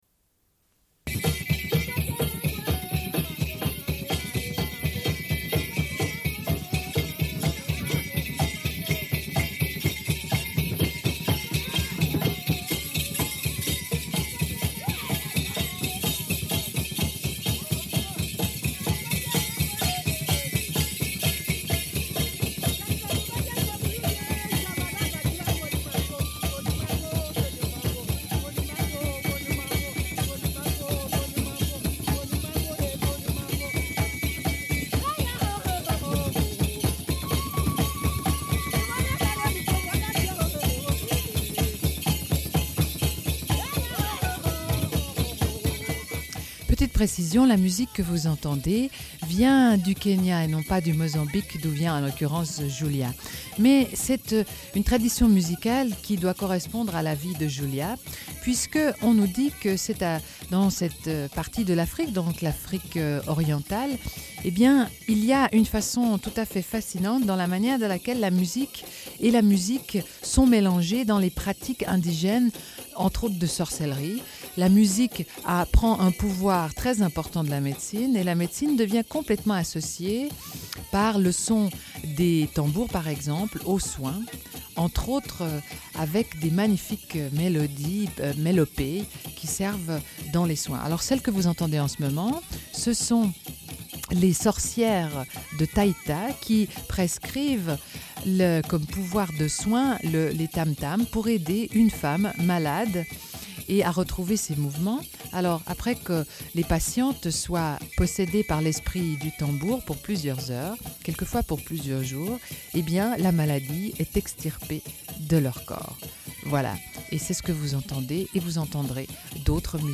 Une cassette audio, face A31:32
Lecture de deux portraits de femmes.